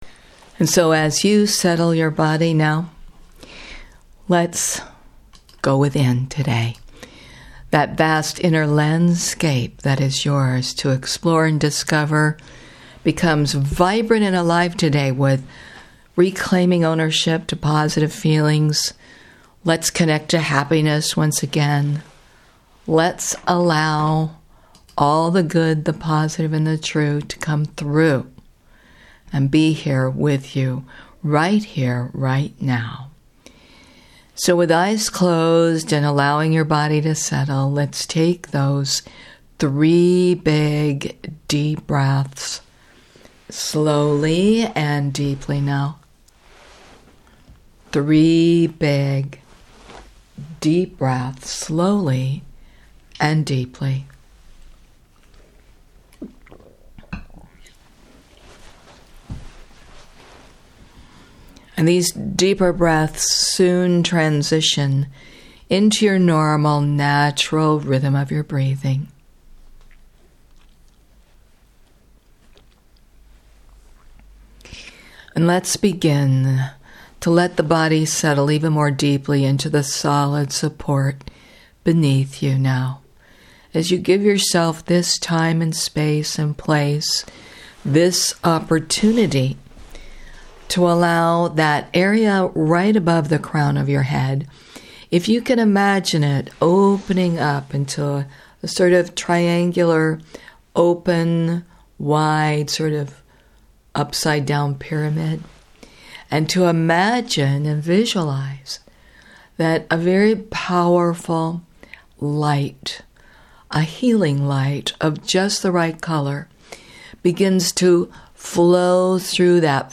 Guided Imagery recorded on October 19